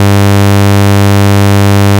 100Hzが正解である。
it[:type] = 'sawtooth'
まぁ、ロードスターを含む、小型車のエンジン音、といわれれば、まぁ、そんな風に聴こえなくもない。